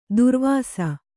♪ durvāsa